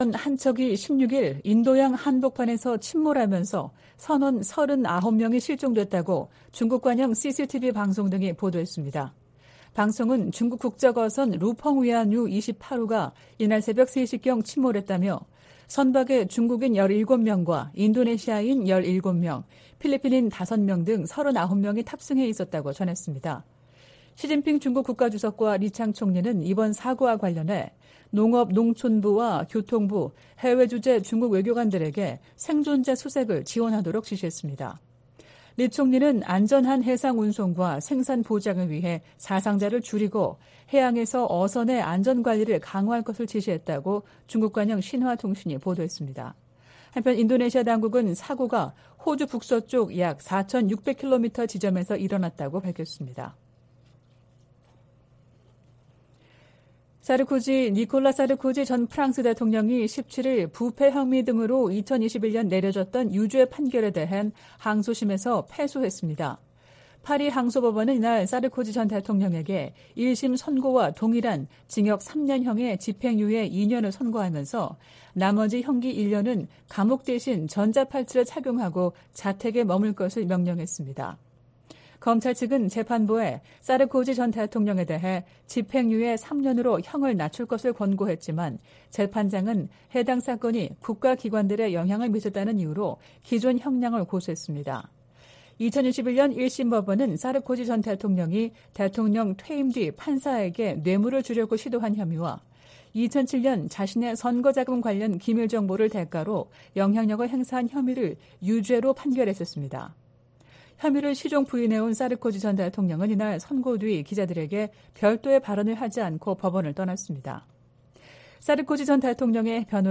VOA 한국어 '출발 뉴스 쇼', 2023년 5월 18일 방송입니다. 로이드 오스틴 미 국방장관은 상원 청문회에서 한국에 대한 확장억제 강화조치를 취하는 중이라고 밝혔습니다. 북한은 우주발사체에 위성 탑재 준비를 마무리했고 김정은 위원장이 '차후 행동계획'을 승인했다고 관영매체들이 전했습니다. 미 국무부가 화학무기금지협약(CWC) 평가회의를 맞아 북한이 생화학무기 프로그램을 보유하고 있다는 평가를 재확인했습니다.